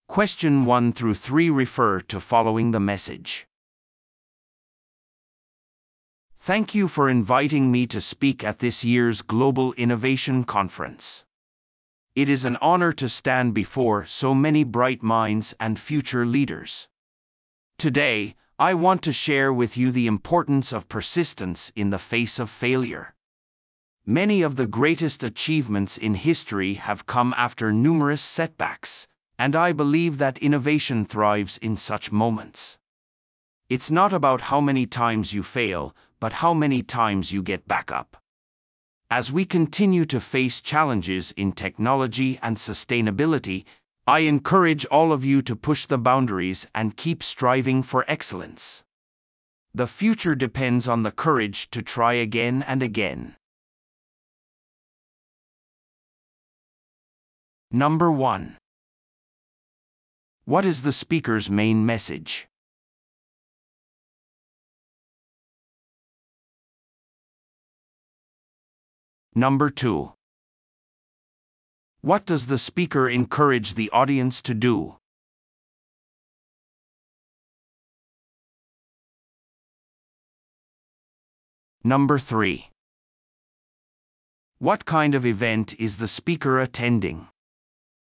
PART４は一人語りの英語音声が流れ、それを聞き取り問題用紙に書かれている設問に回答する形式のリスニング問題。